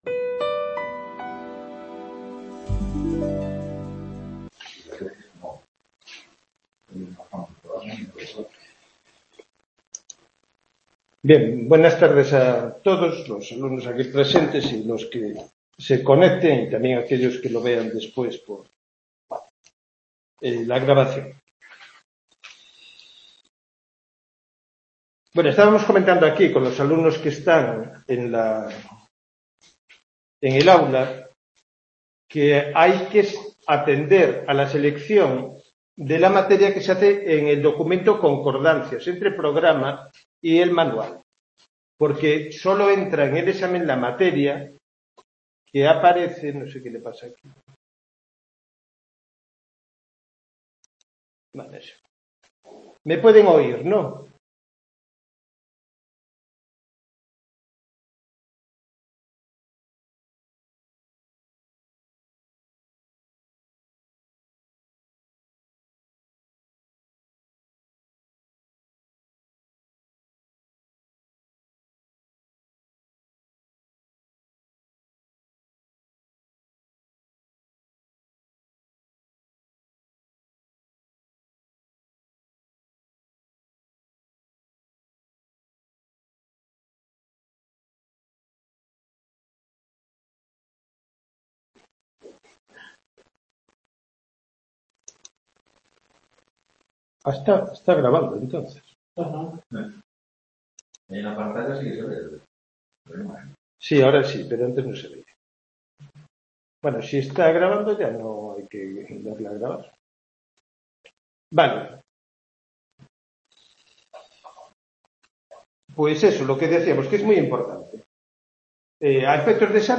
Tutoría